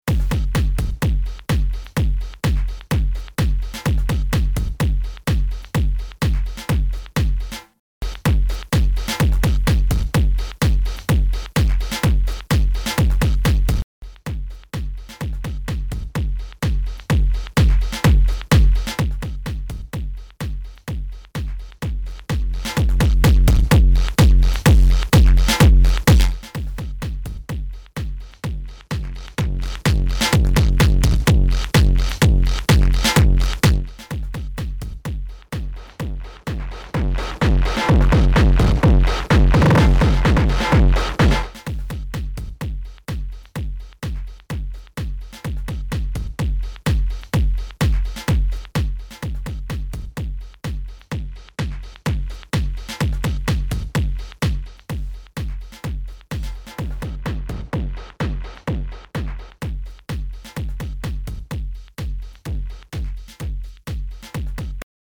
AR only: 00-07 AR (no MSTR DSTR) 08-13 (MSTR DSTR appx 80%)
AR + AH: 14-41 (Drive 100% Clean, Sat, Enh circuits) 42-end (Drive appx 50% Clean, Sat, Enh circuits)
Apologies for not matching gain, but I think you get an idea.
looks like the rytms distortion is more of a glue than a full on effect and if i want crazy mangled distortion Analog Drive is the way to go